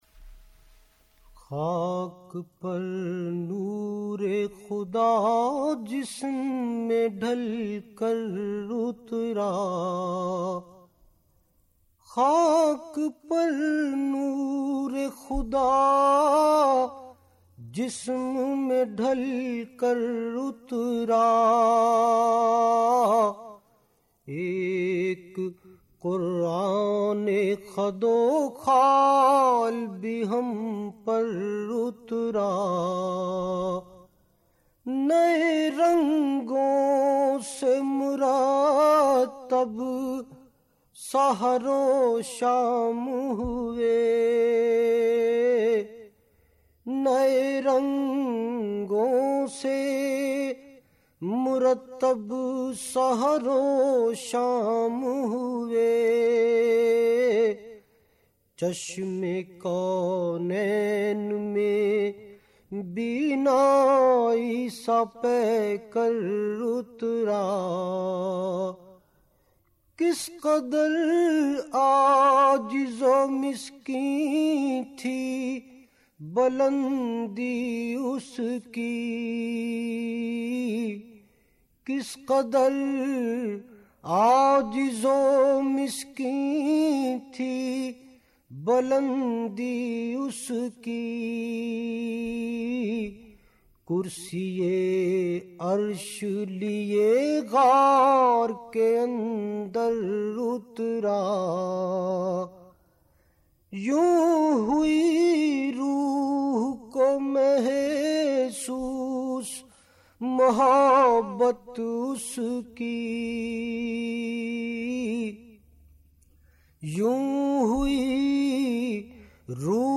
نعت رسول مقبول صلّٰی اللہ علیہ وآلہ وسلم